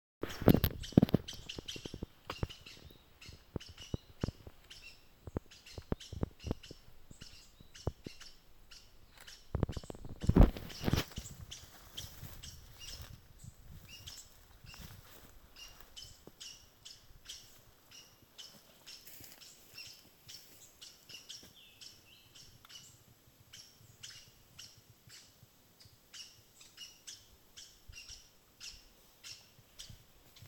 Arasarí Banana (Pteroglossus bailloni)
Nombre en inglés: Saffron Toucanet
Localidad o área protegida: Reserva Papel Misionero
Condición: Silvestre
Certeza: Observada, Vocalización Grabada